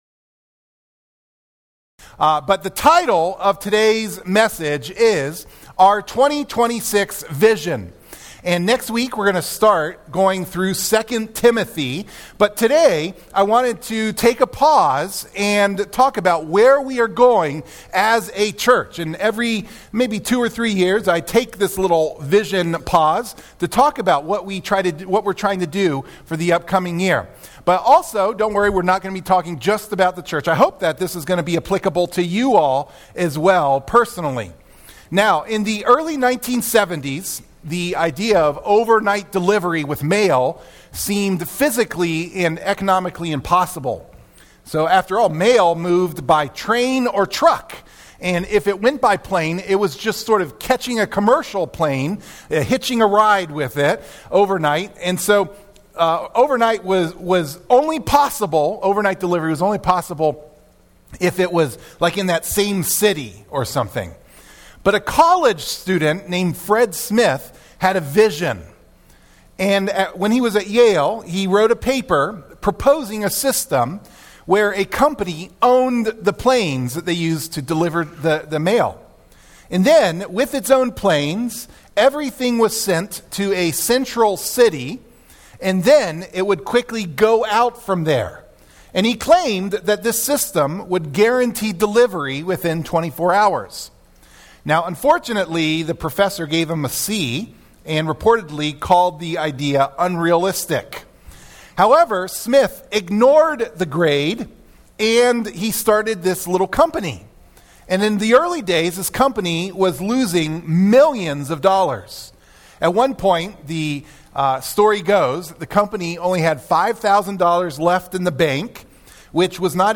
Sermon-1-4-26-MP3-for-Audio-Podcasting.mp3